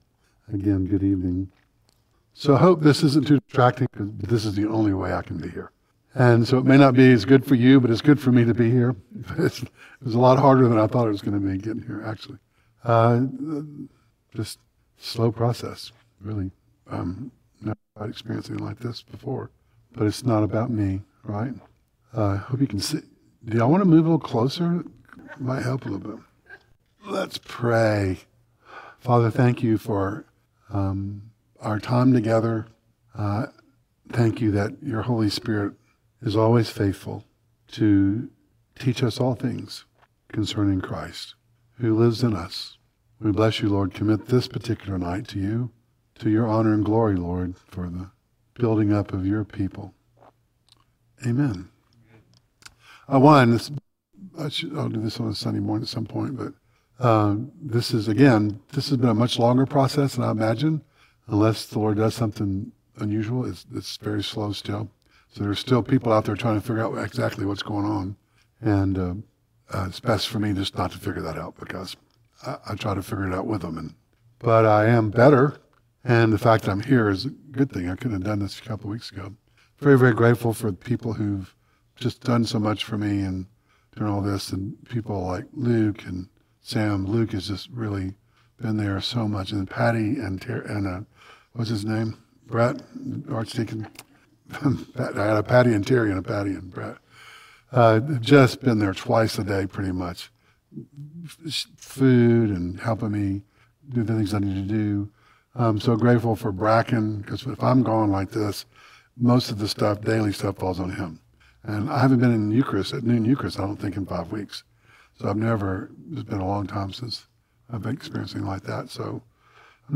Audio Devotionals Service Type: Wednesday Night 1Thessalonians 5:16-18